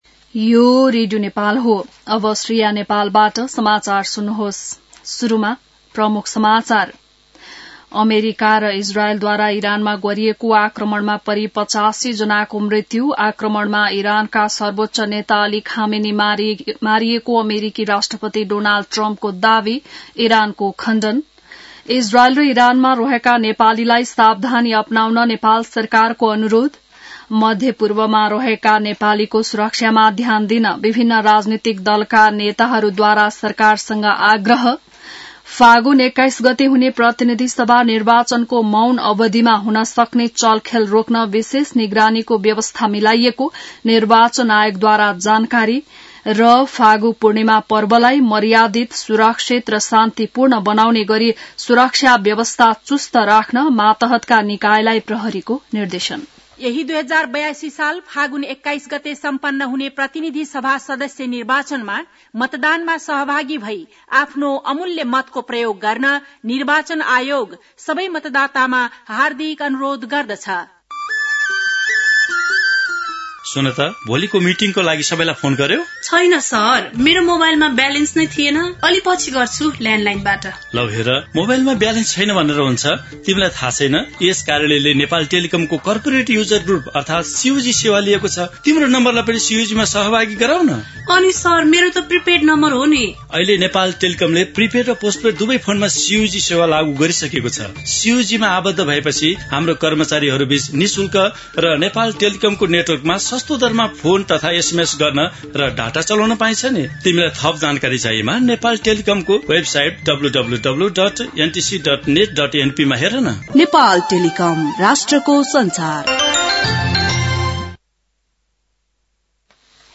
बिहान ७ बजेको नेपाली समाचार : १७ फागुन , २०८२